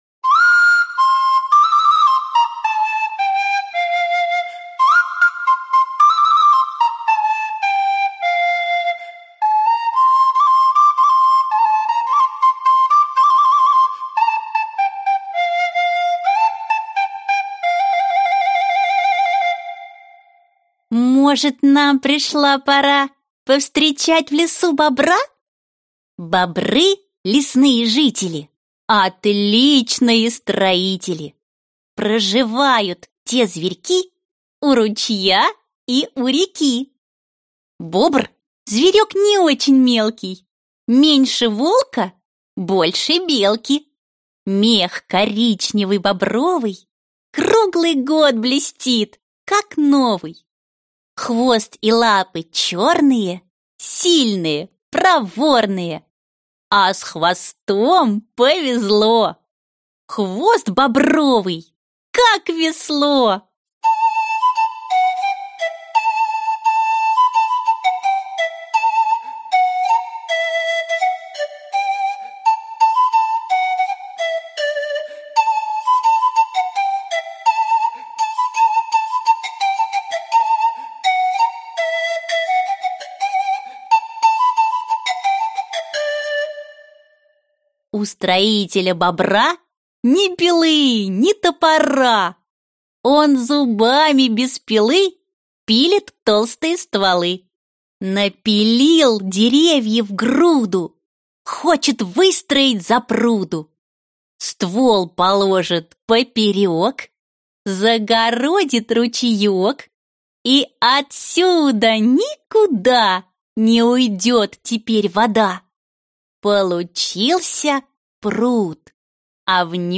Аудиокнига Кто живет в лесу | Библиотека аудиокниг